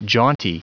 Prononciation du mot jaunty en anglais (fichier audio)
Prononciation du mot : jaunty